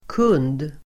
Uttal: [kun:d]